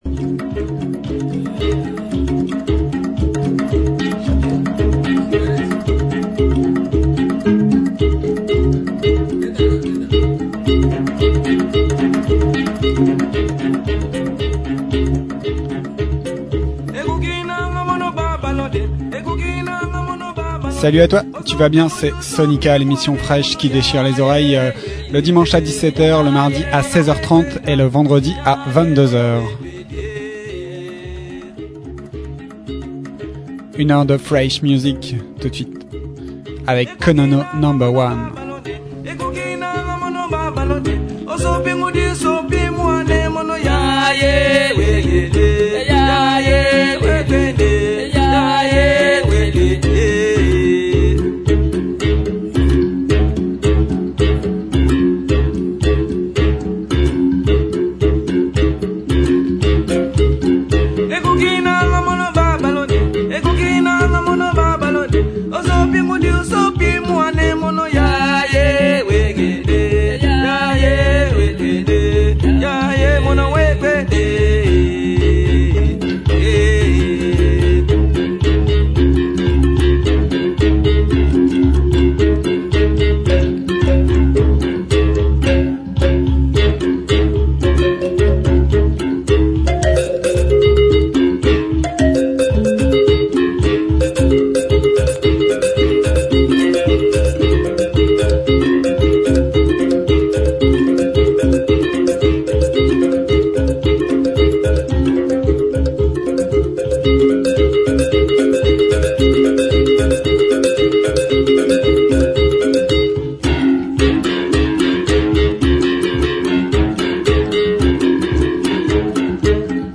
SoniKa en live sur RADIO PFM 99.9 le dimanche à 17h !!